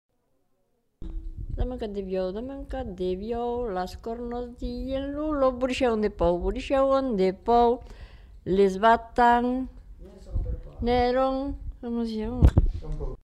Aire culturelle : Savès
Genre : chant
Effectif : 1
Type de voix : voix de femme
Production du son : chanté